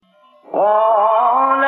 مقام سيكا ( تلاوة )